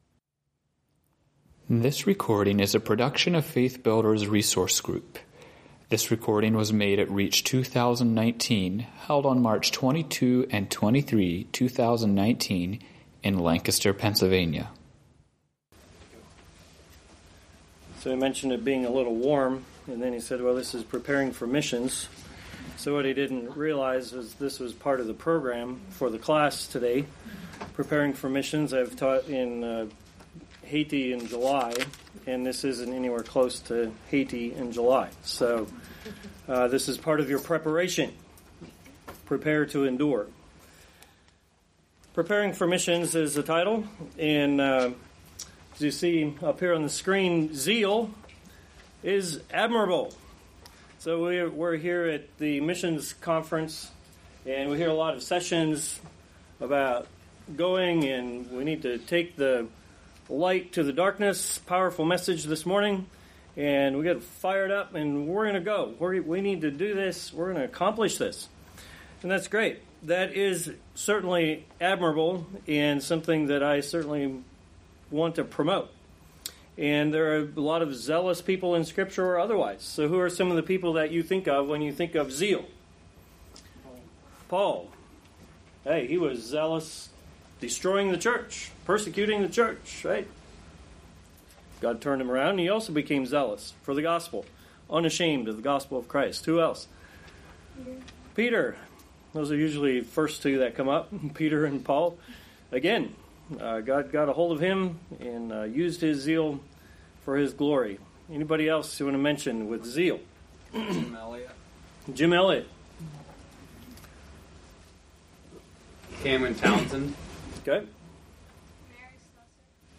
Home » Lectures » Preparing for Missions